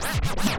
scratch16.wav